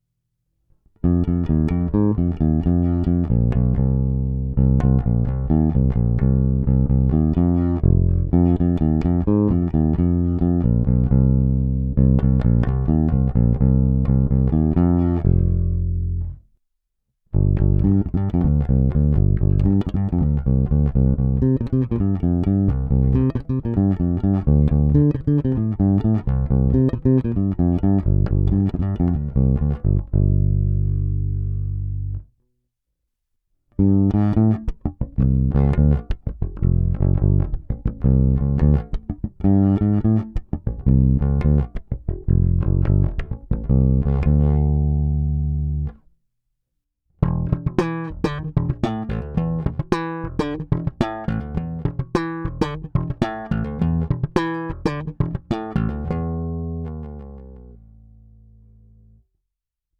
Není-li uvedeno jinak, nahrávky jsou provedeny rovnou do zvukové karty, bez stažené tónové clony a bez použití korekcí.
Hráno nad použitým snímačem, v případě obou hráno mezi nimi.